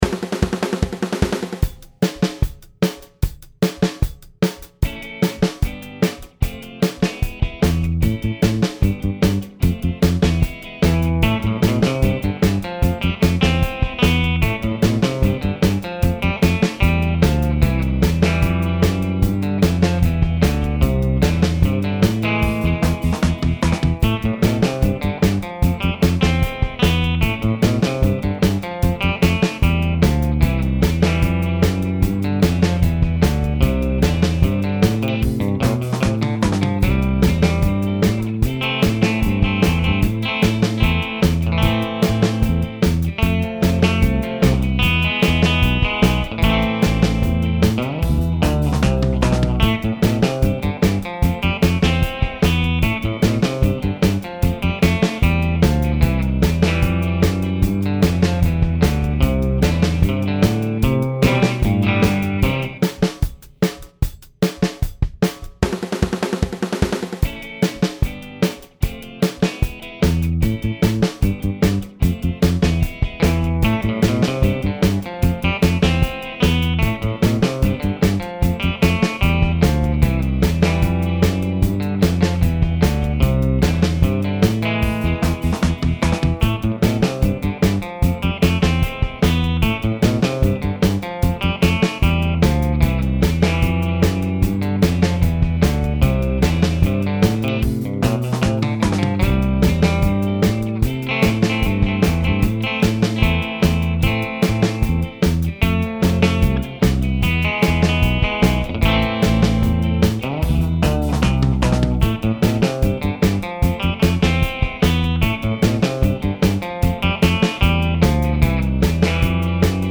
きらめくギター、ベース、アジなキーボード